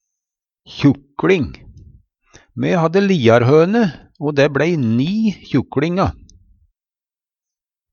kjukLing - Numedalsmål (en-US)